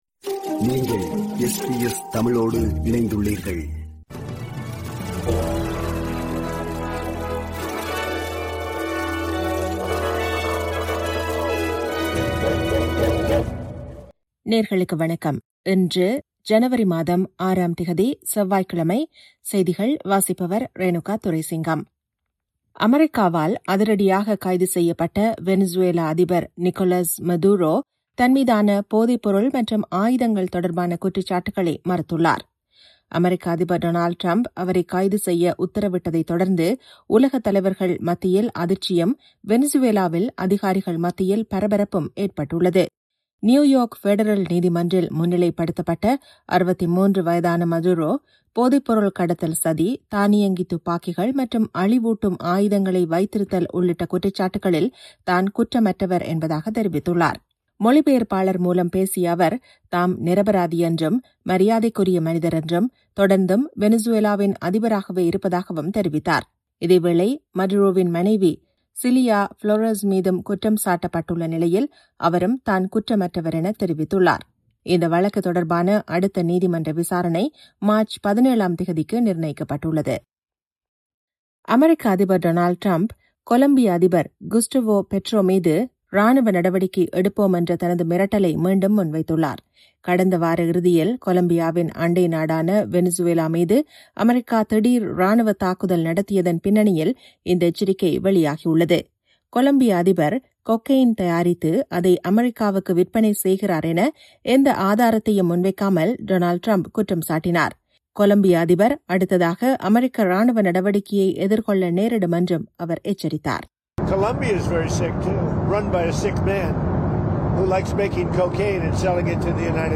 SBS தமிழ் ஒலிபரப்பின் இன்றைய (செவ்வாய்க்கிழமை 06/01/2026) செய்திகள்.